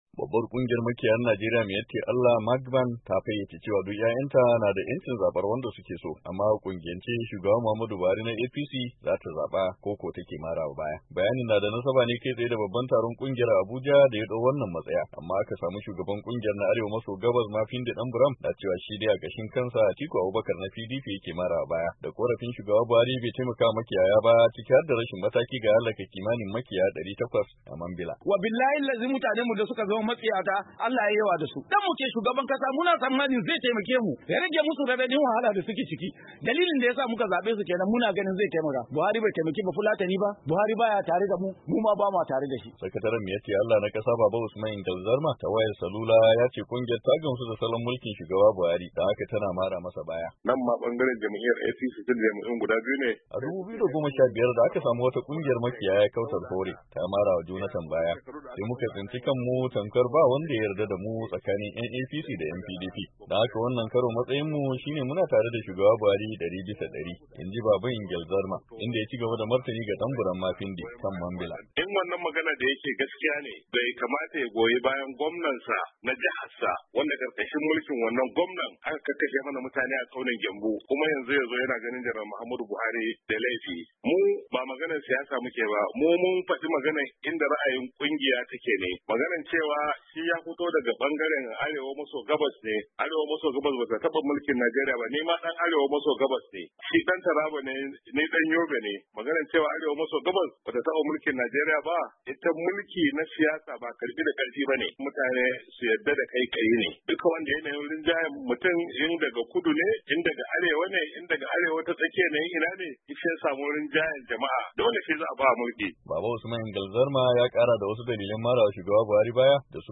WASHINGTON DC, —